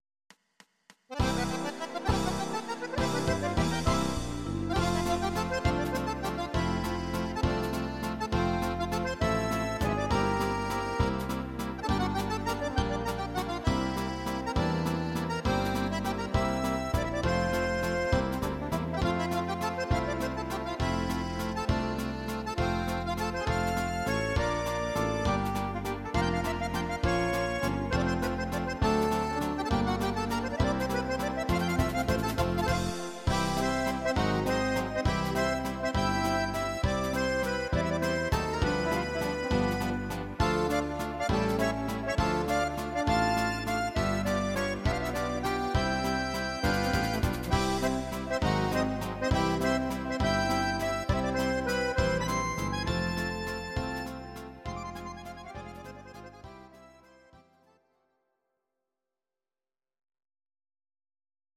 These are MP3 versions of our MIDI file catalogue.
Please note: no vocals and no karaoke included.
Akkordeon